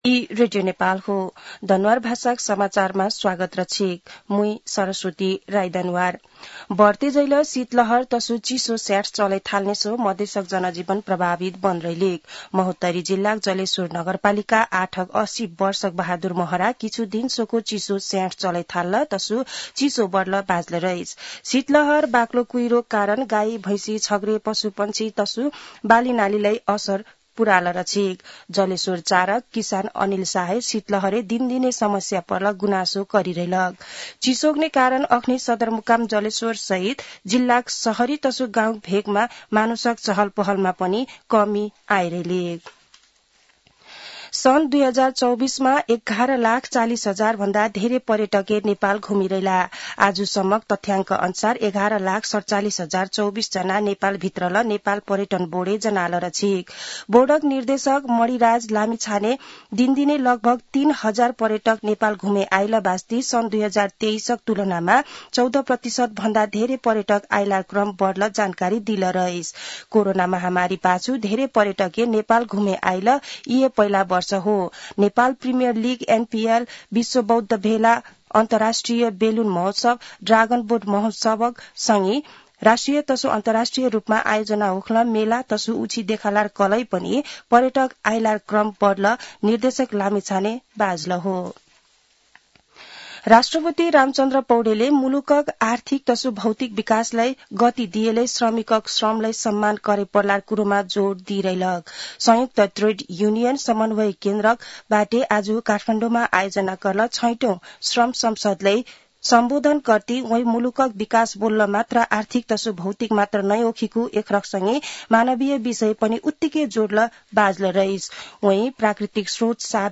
दनुवार भाषामा समाचार : १७ पुष , २०८१